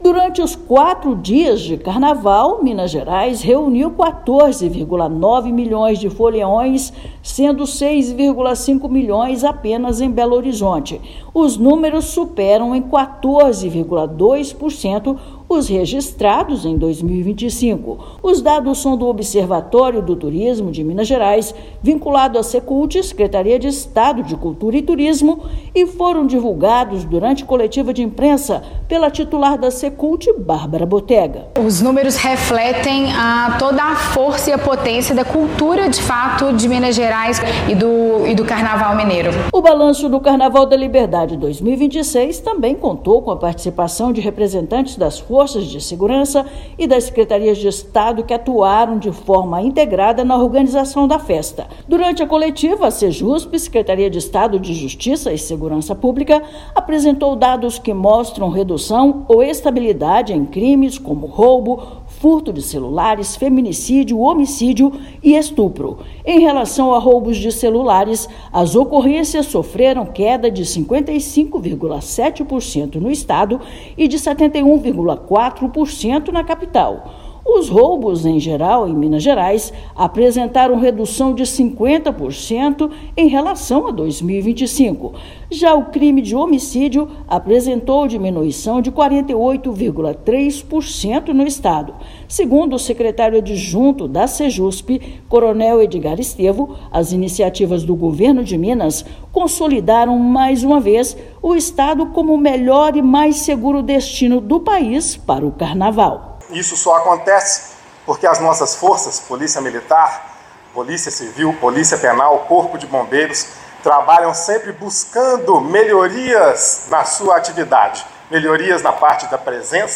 Folia mineira injetou cerca de R$ 5,83 bilhões na economia do estado, alta de 10% em relação ao ano anterior, com crescimento expressivo da ocupação hoteleira e do Carnaval das Cidades Históricas, marcando o estado como destino plural da folia. Ouça matéria de rádio.